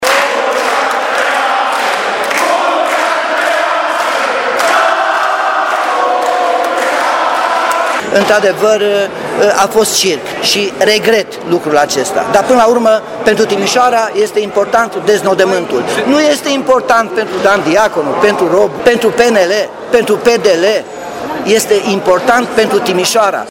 Primarul Timișoarei, Nicolae Robu, a reiterat ideea că în spatele încercării de schimbare a viceprimarului Diaconu se află fostul primar, Gheorghe Ciuhandu: